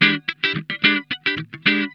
HOT CHUG 1.wav